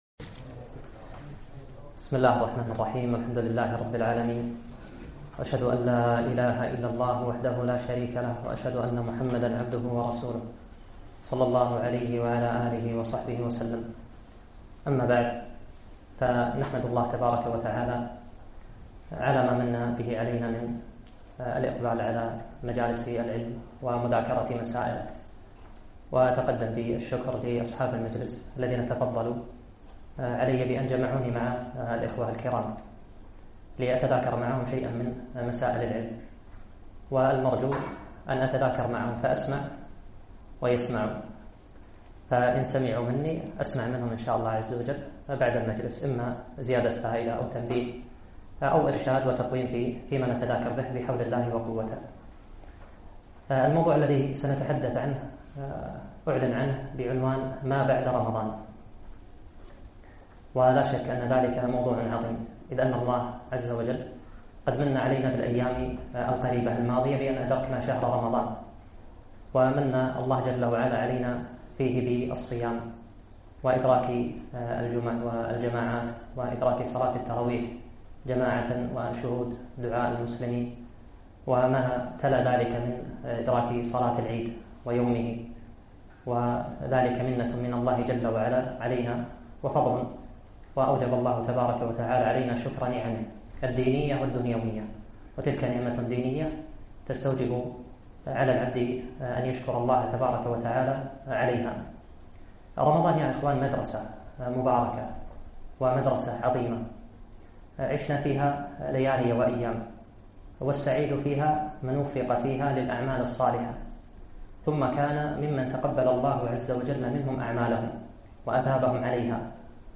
محاضرة - ماذا بعد رمضان وحقيقة التقوى